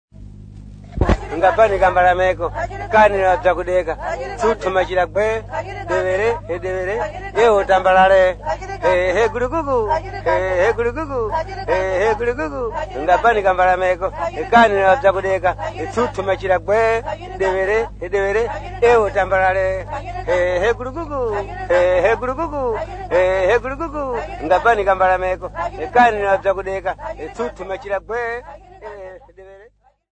Tavara women at Shangara, Tete
Folk Music
Field recordings
sound recording-musical
Indigenous music